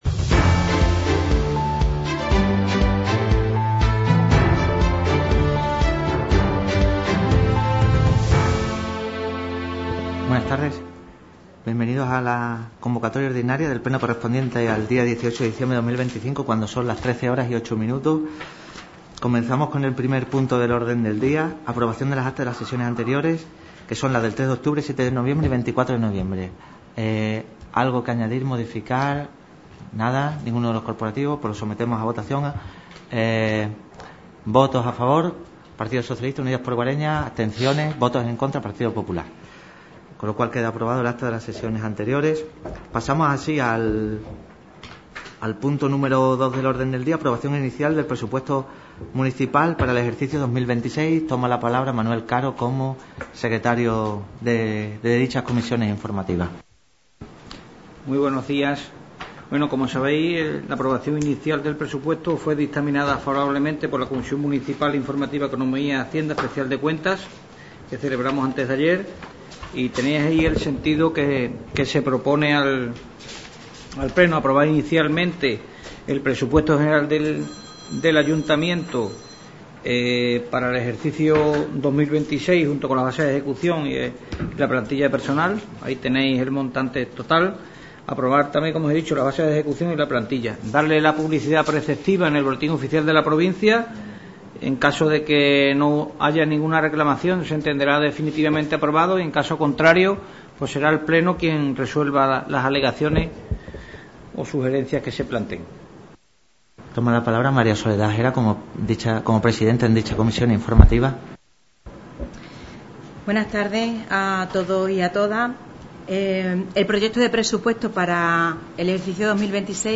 Pleno Ordinario 18.12.2025 (Audio íntegro) – Ayuntamiento de Guareña
Sesión celebrada en el Ayuntamiento de Guareña.